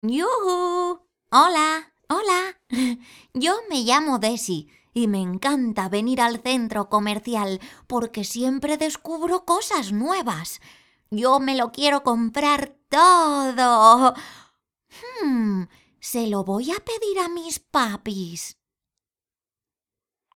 Voz media.
Sprechprobe: Sonstiges (Muttersprache):